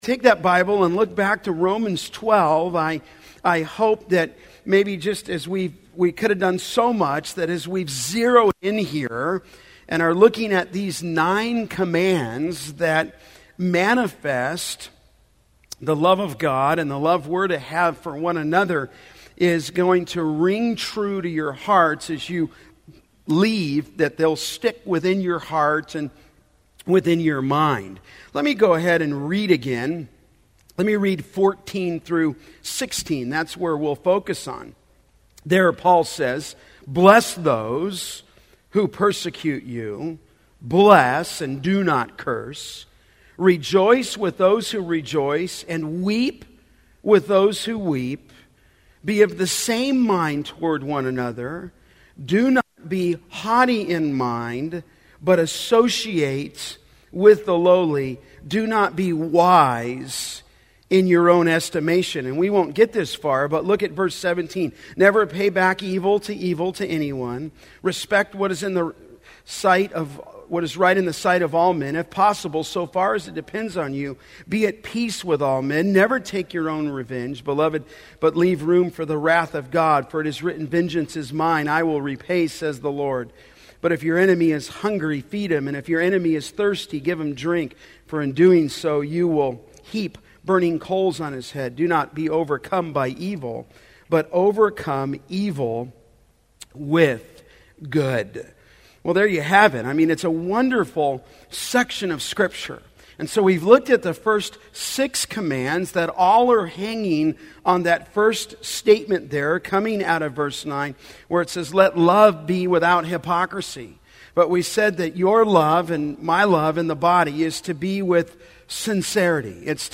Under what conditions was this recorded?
Men Men's Retreat - 2013 Audio Series List Next ▶ Current 1.